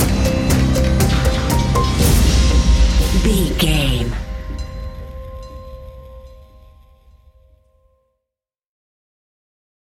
Thriller
Ionian/Major
C♭
dark ambient
EBM
synths